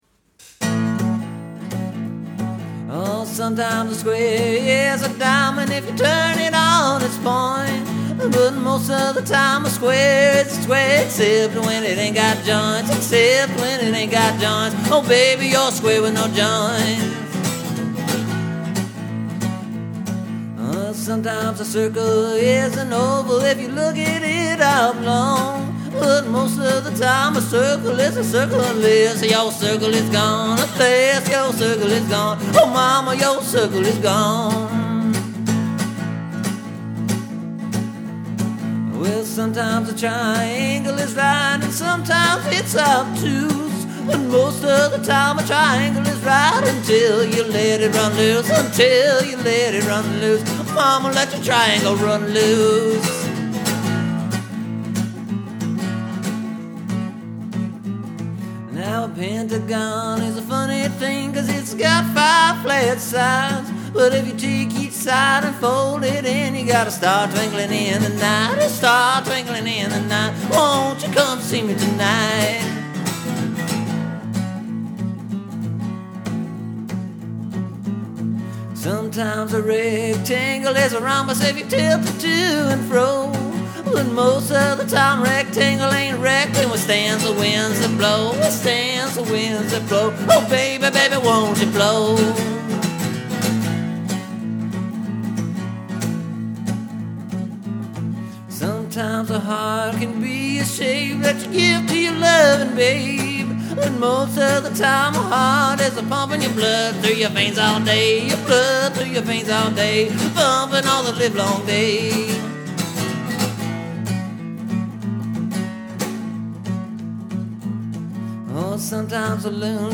Okay okay okay…here’s the updated and re-recorded version of the song “Shapes” that I first wrote and released this week, last year.
This new version for this year sounds almost exactly like how I sing and play a lot of other songs I’ve written this year.
This new 2011 version, though, is probably pretty much only about one thing, but it’s hard to nail that down cause the music to the song makes you just start to wiggle and move before you can listen to any of the words.